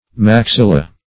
Maxilla \Max*il"la\, n.; pl.